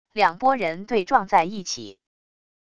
两拨人对撞在一起wav音频